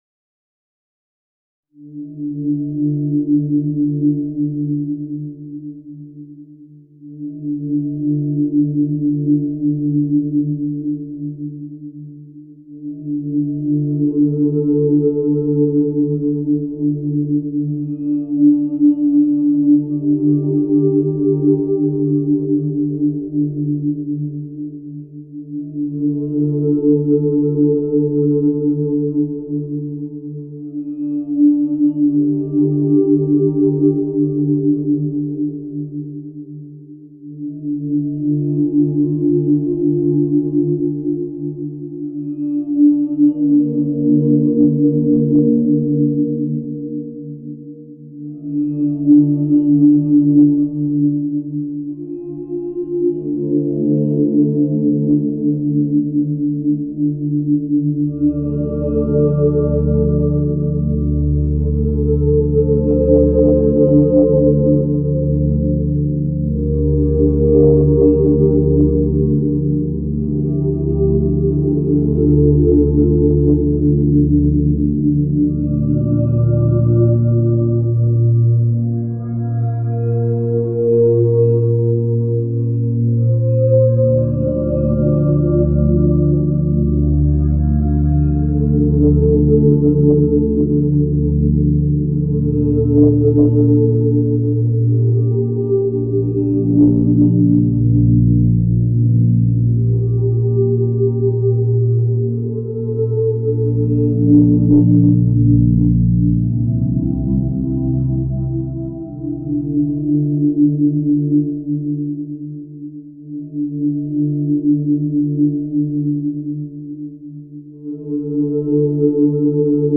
Yoga - Méditation - Relaxation
Qu'il s'agisse de pulsations ambiantes ou de douces mé...